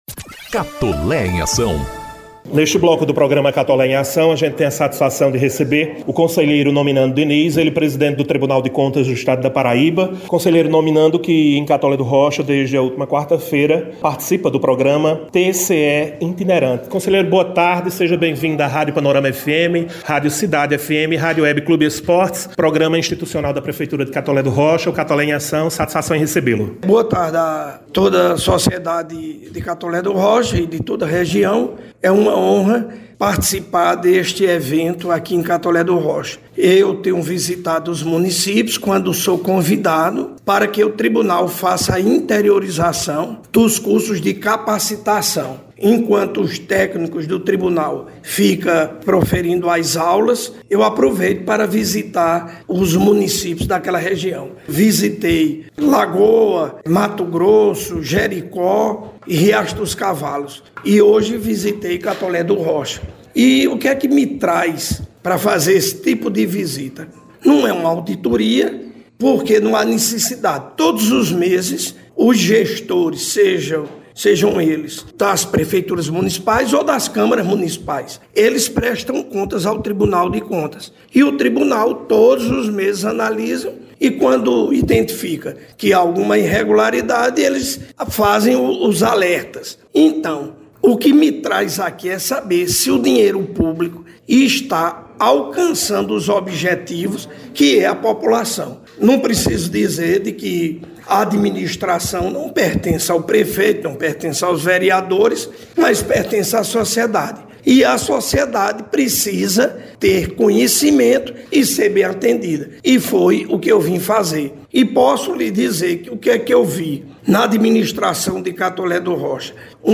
As declarações foram feitas durante a entrevista exibida no Programa Institucional “Catolé em Ação” – edição n° 105 -, sexta-feira (15/09), que também destacou os avanços das plataformas: Sagres, Portal da Transparência, Tramita e Ajunta.
G-ENTREVISTA-Conselheiro-Nominando-Diniz-Presidente-do-TCE-PB-e-o-Prefeito-Laurinho-Maia.mp3